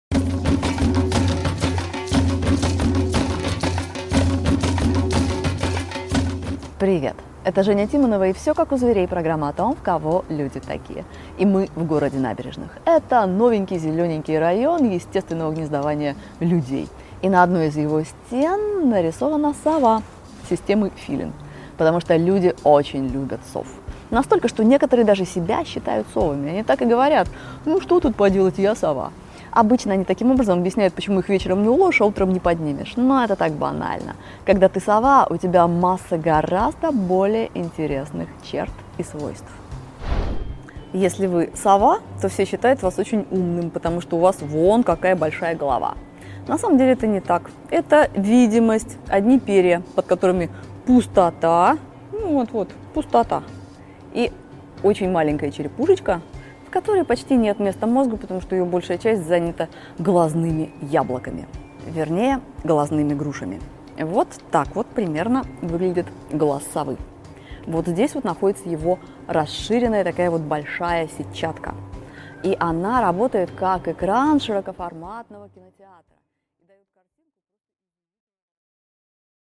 Аудиокнига Совы не то, чем кажутся | Библиотека аудиокниг